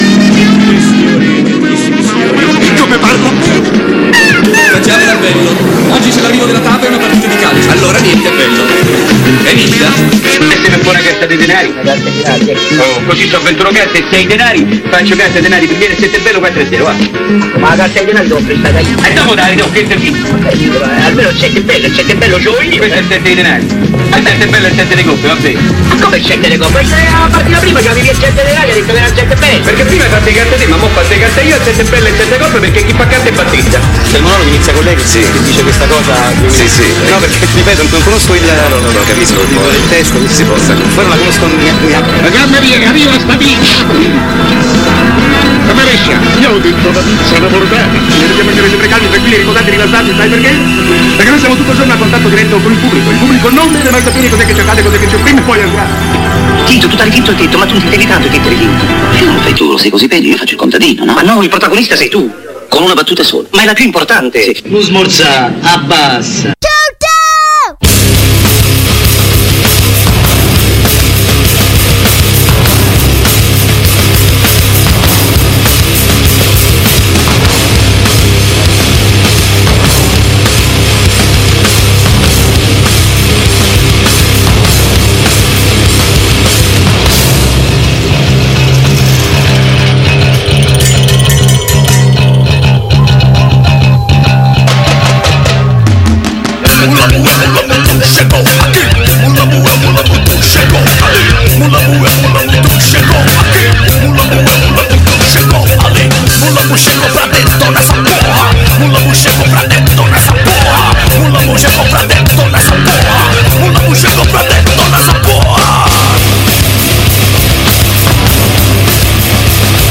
L’appuntamento del mercoledì si è rinnovato anche questa settimana con una intervista a una formazione musicale con due elementi.